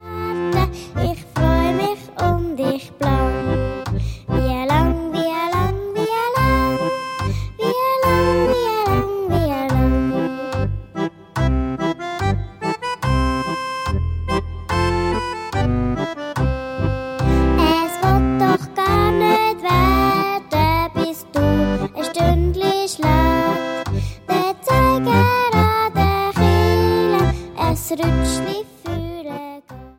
Alte Schweizer Weihnachtslieder sanft renoviert